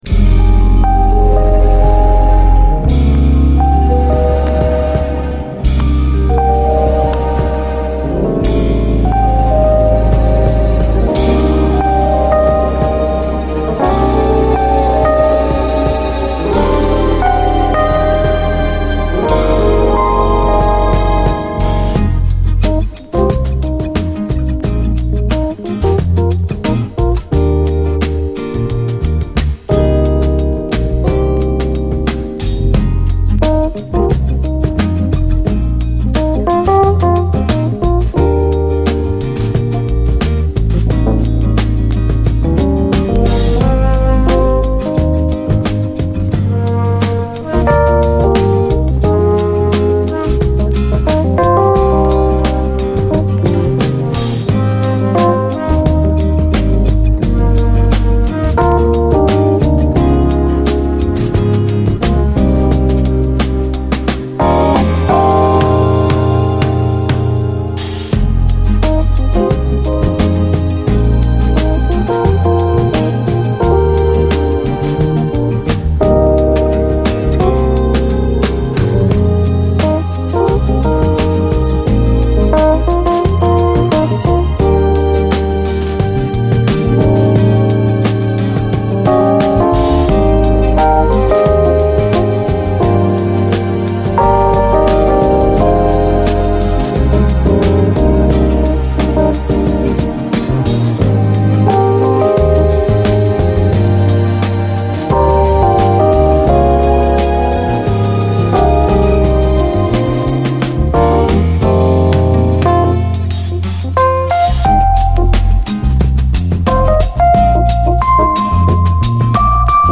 SOUL, JAZZ FUNK / SOUL JAZZ, 70's～ SOUL, JAZZ, 7INCH
のイントロに数回大きくノイズあり。
の名作メロウ・ジャズ・ファンク・カヴァーのピンポイントな７インチ！エレピ、フルートの音色がホント美しいです。